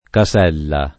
casella [
kaS$lla] s. f. — sim. il top., pers. m. stor. e cogn. Casella e il top. Caselle (es.: Caselle Lurani [kaS$lle lur#ni], Lomb.; Caselle in Pittari [